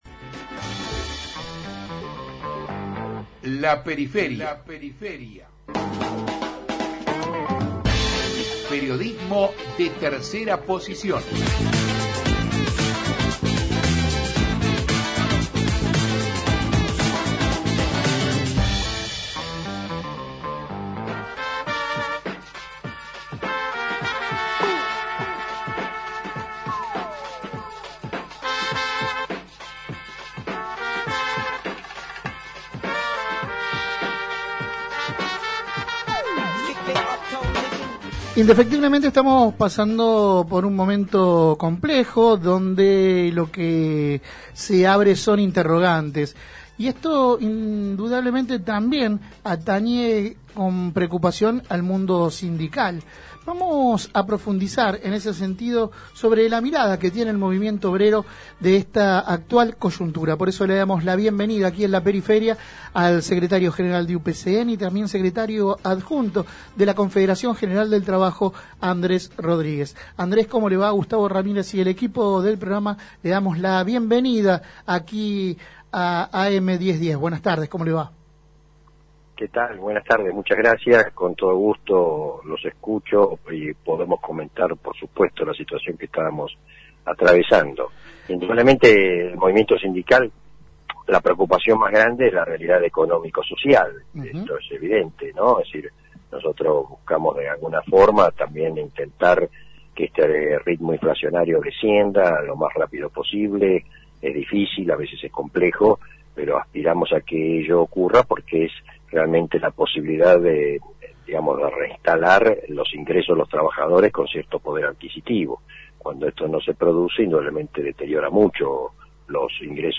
Compartimos la entrevista completa: